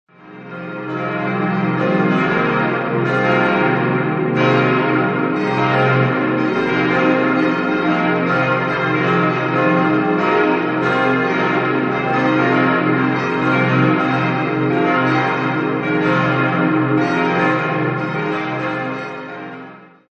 5-stimmiges ausgefülltes und erweitertes C-Moll-Geläute: c'-es'-f'-g'-b'
Die Glocken wurden 1958 von Friedrich Wilhelm Schilling in Heidelberg gegossen.